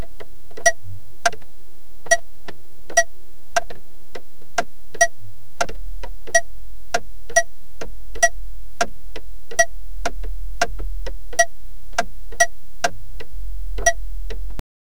Royalty-free car-interior sound effects
real-sound-of-turn-signal-hoqkaj7l.wav